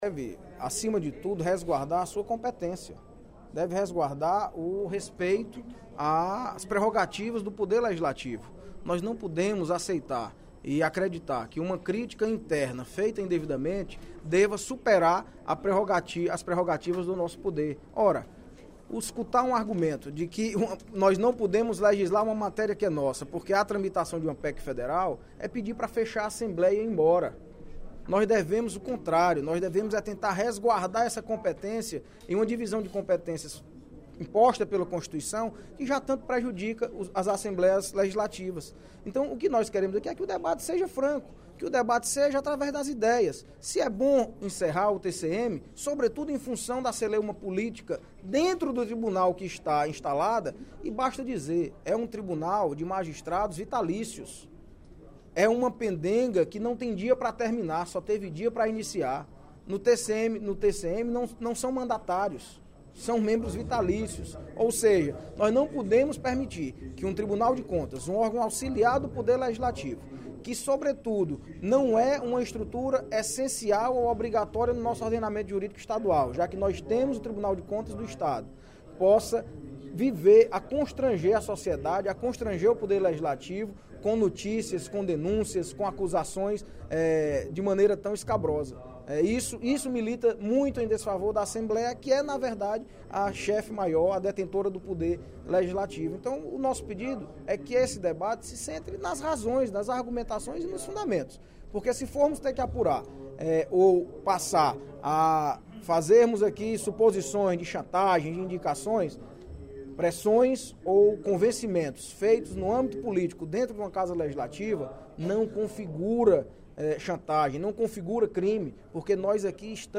O deputado Audic Mota (PMDB) pediu, no primeiro expediente da sessão plenária desta quinta-feira (18/05), respeito e cautela entre os demais parlamentares durante a tramitação da proposta de emenda à Constituição (PEC) n° 07/17, que extingue o Tribunal de Contas dos Municípios (TCM).